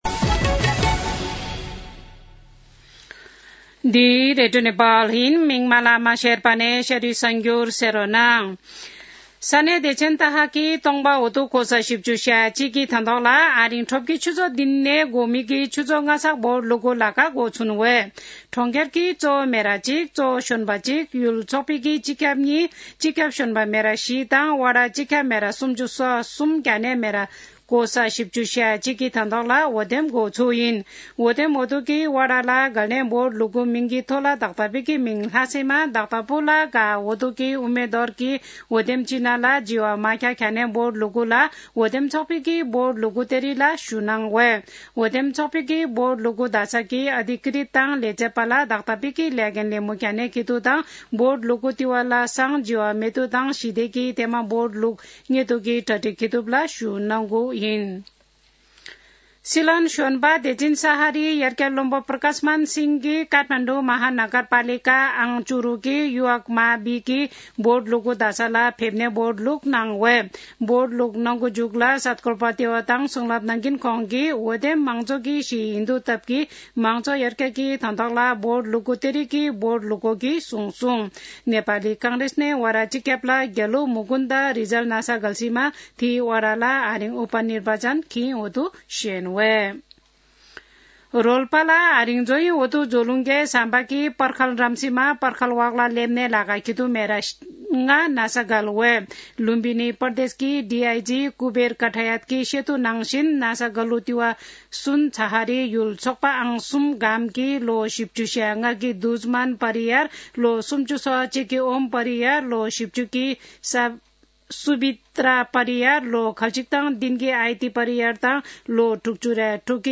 शेर्पा भाषाको समाचार : १७ मंसिर , २०८१
Sherpa-News-16.mp3